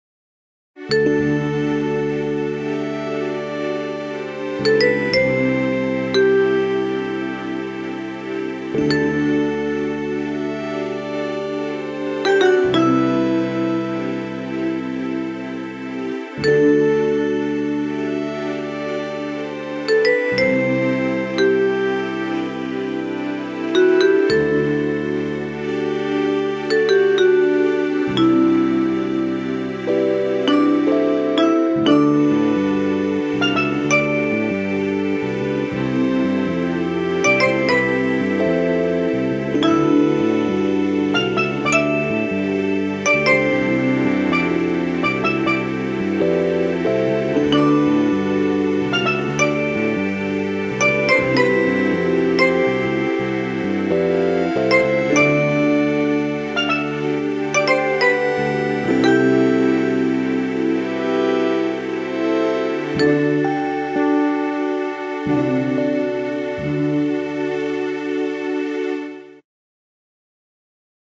Carribean Jungle Adventure RPG style